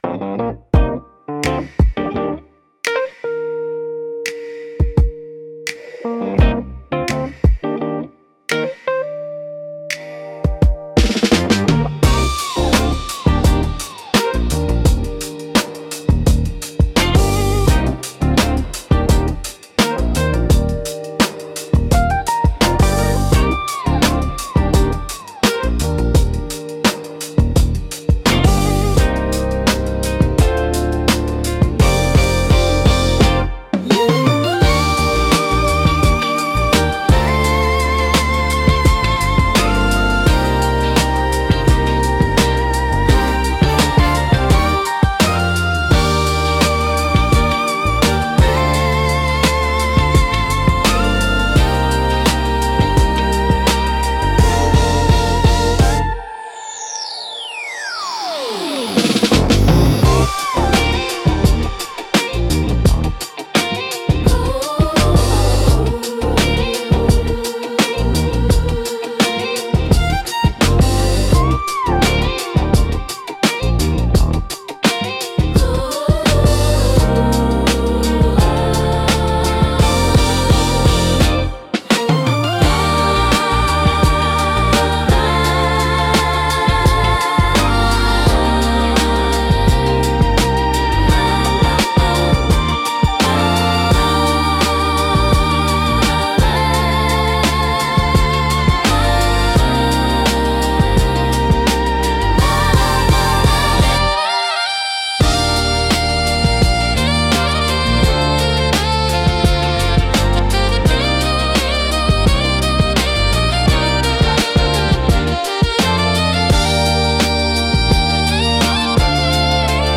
リズムの重厚感とグルーヴ感、感情豊かなボーカルが特徴で、深みと熱量のあるサウンドが魅力です。